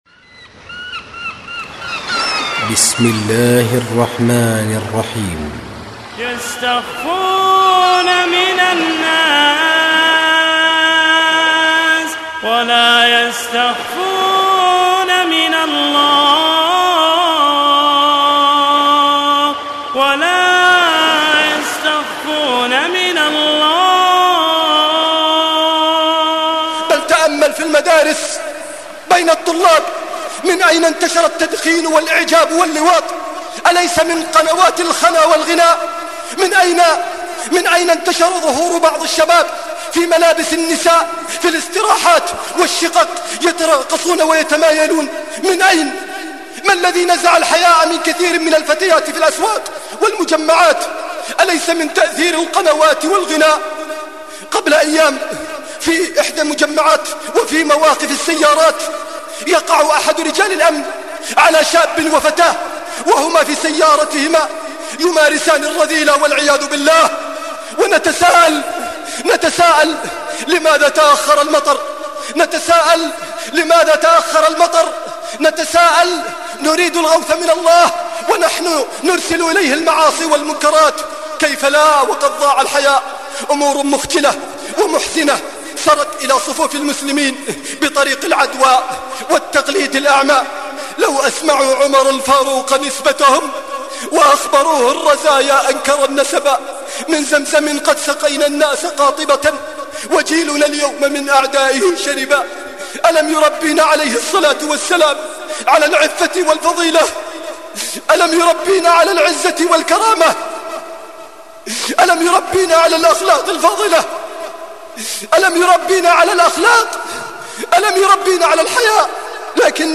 عنوان المادة فكانت هذه الخاتمة عند موته..)محاضرة مؤثرة جدا)